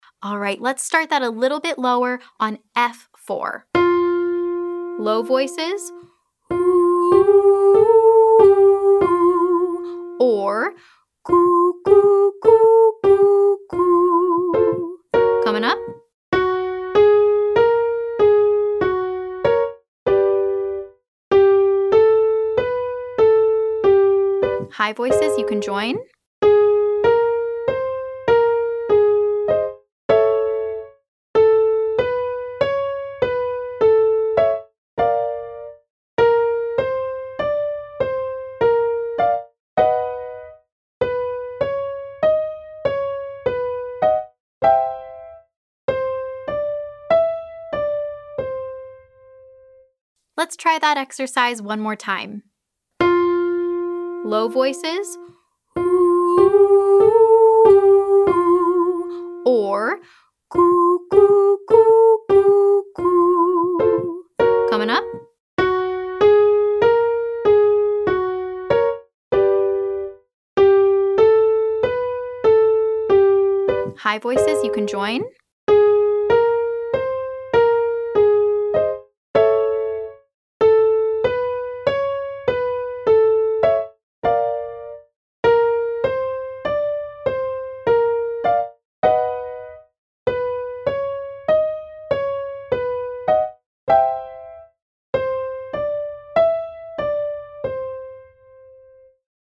• HOO/CUCKOO 12321